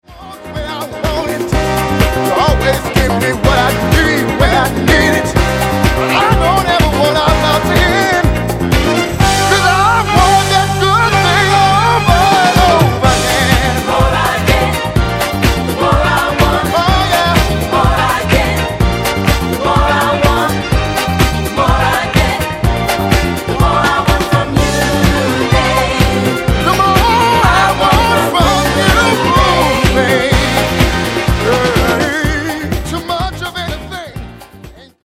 Genere:   Disco|Soul | Funky